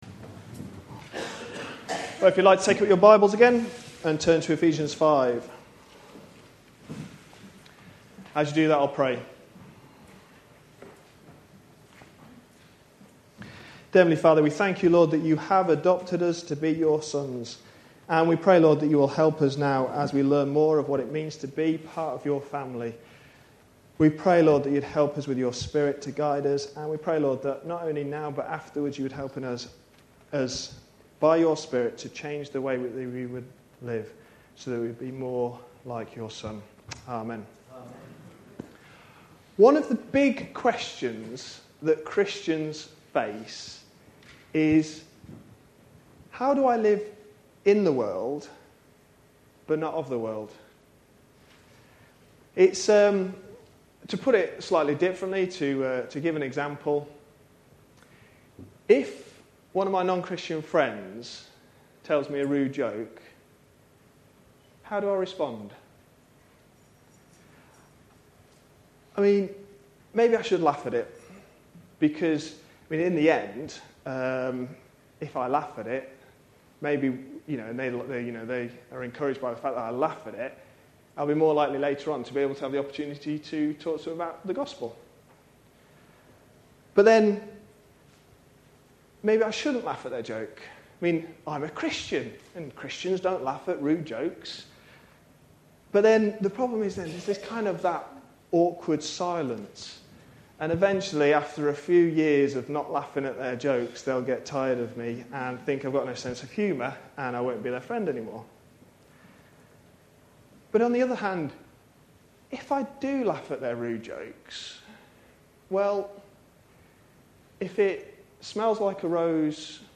A sermon preached on 13th March, 2011, as part of our Ephesians series.